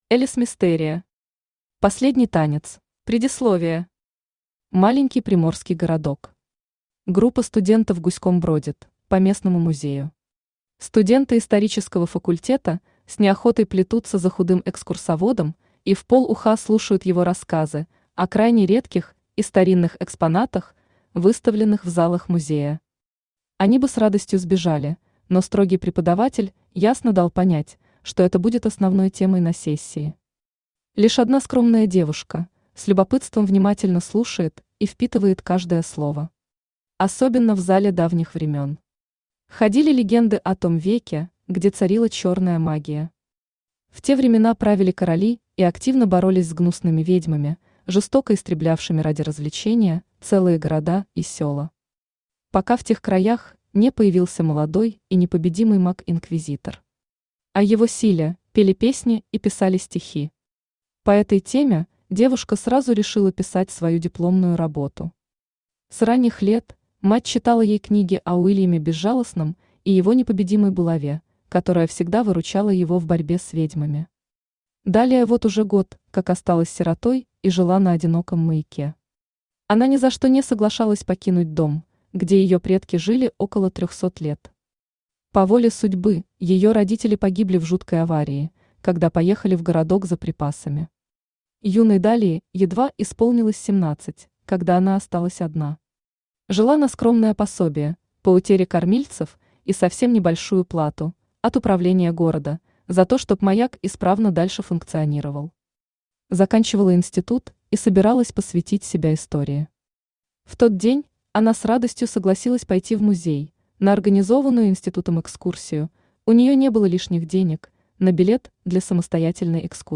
Aудиокнига Последний танец Автор Элис Мистерия Читает аудиокнигу Авточтец ЛитРес.